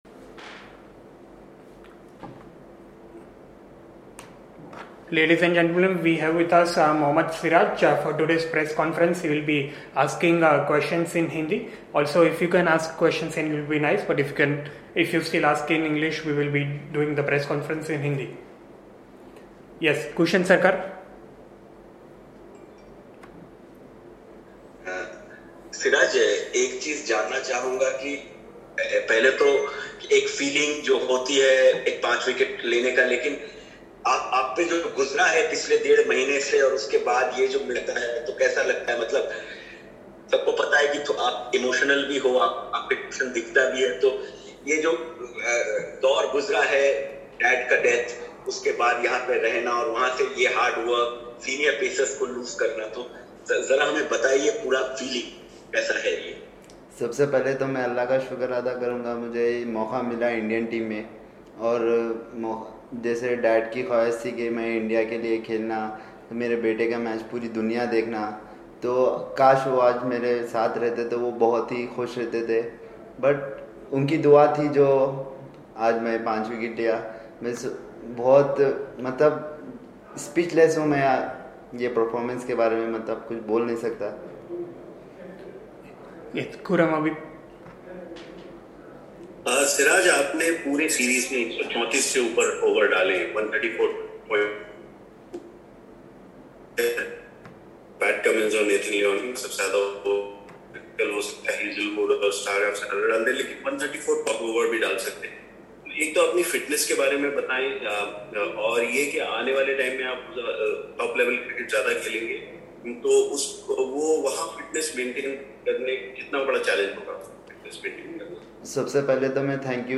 Mohammed Siraj speaks after Day 4 of the 4th Test vs Australia